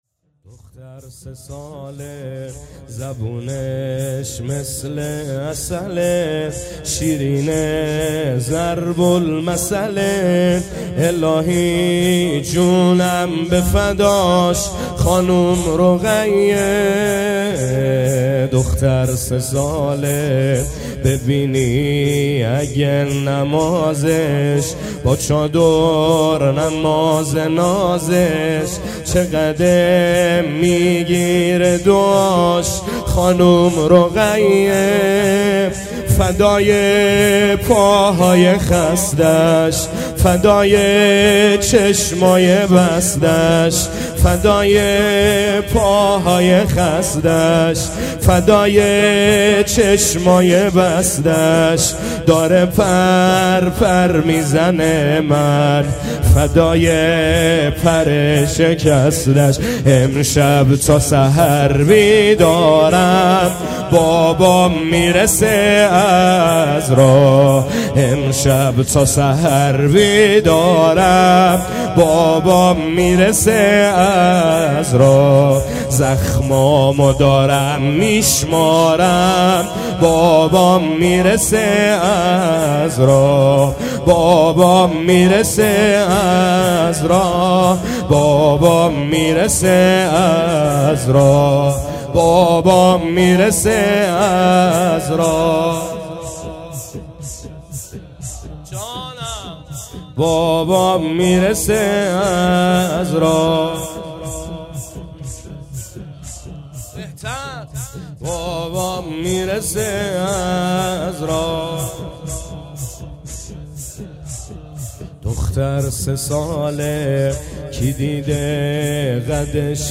زمینه | دختر سه ساله شیرینه